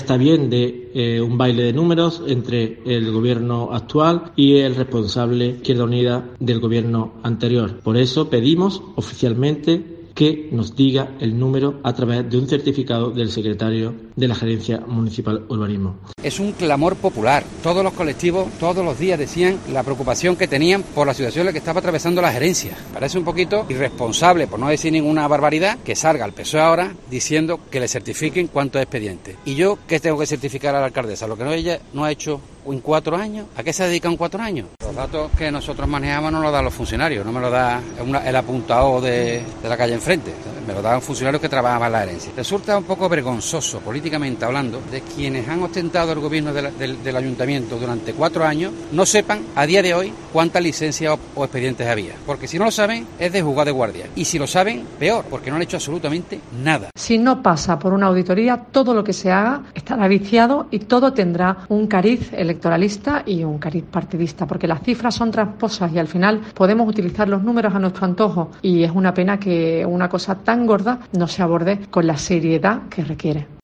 Escucha a los dirigentes de PP, PSOE y VOX sobre las licencias sin resolver en Urbanismo